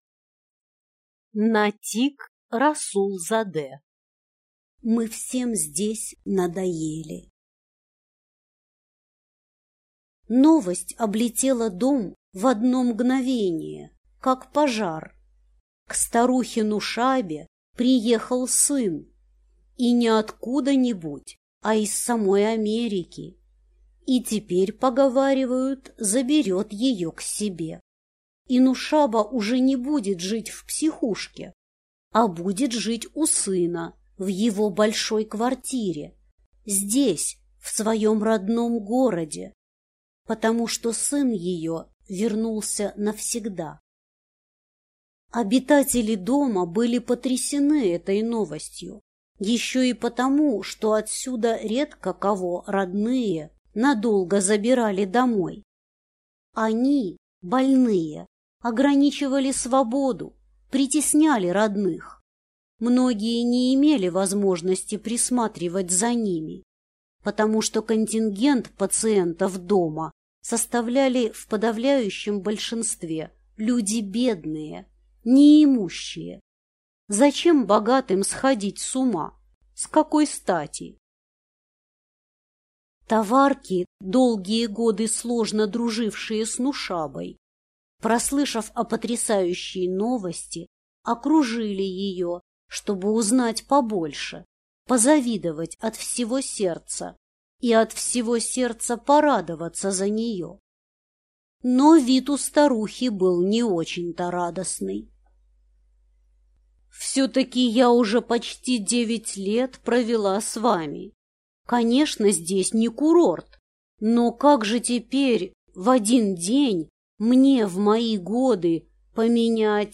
Аудиокнига Мы всем здесь надоели | Библиотека аудиокниг